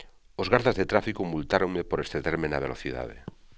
os GárDas De tráfiko multároNme por eksTeDérme na BeloTiDáDe.